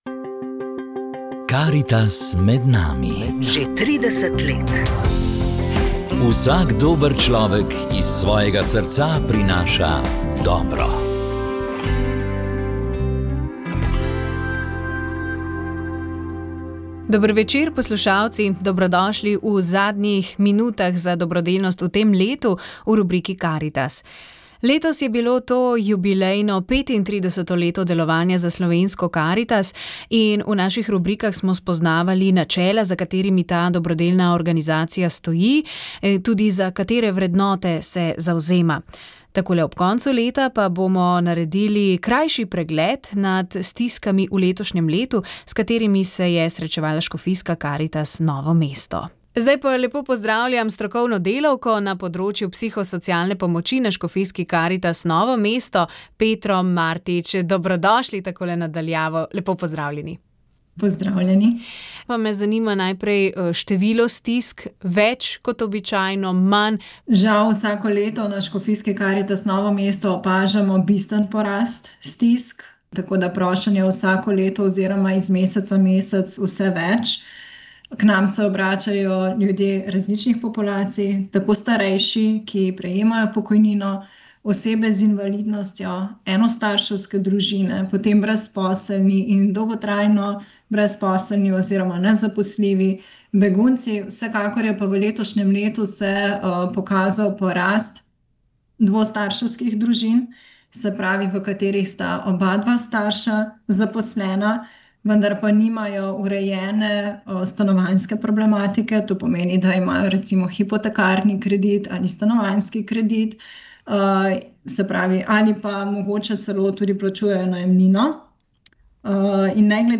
V oddaji Karitas je bil naš sogovornik predsednik Slovenske karitas, mariborski nadškof Alojzij Cvikl. Vprašali smo ga, kako ocenjuje delo karitativnih sodelavcev in prostovoljcev v letu, ki je za nami, in kakšni izzivi čakajo Karitas v novem letu.